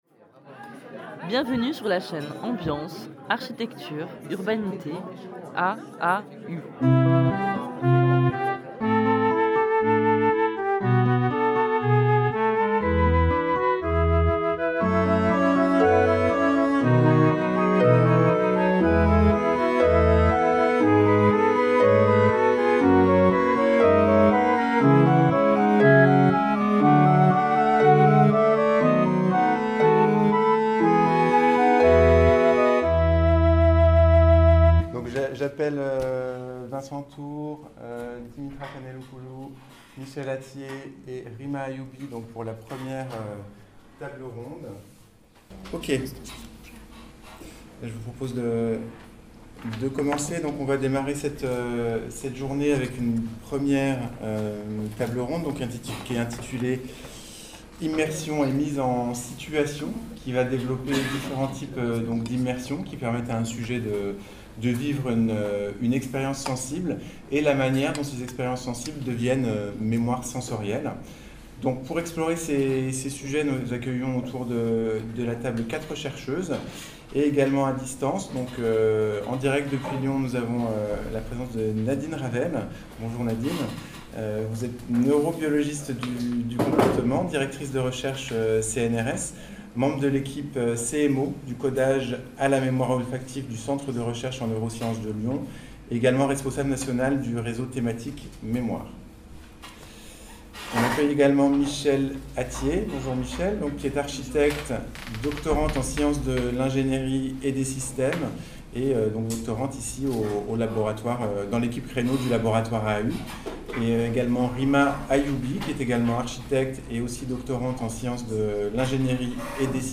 Table ronde 1 : Immersions et mises en situations | Canal U